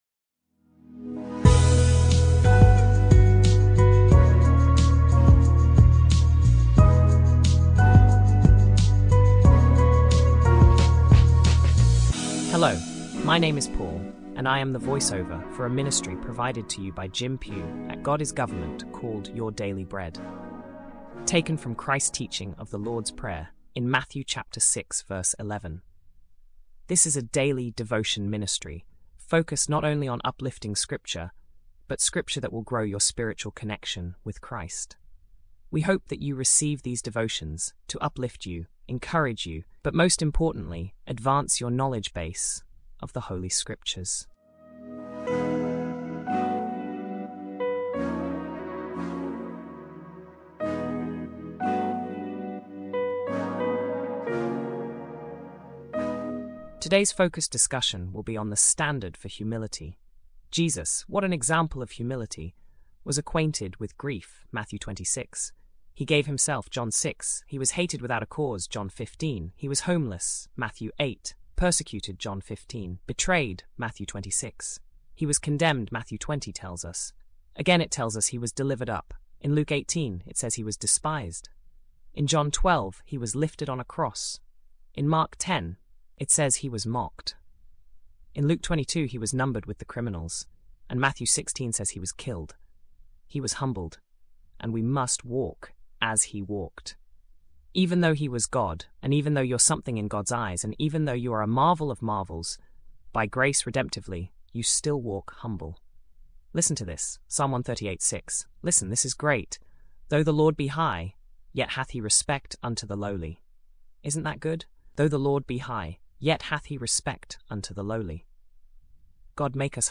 This is a daily devotion ministry focused not only on uplifting Scripture, but Scripture that will grow your spiritual connection with Christ.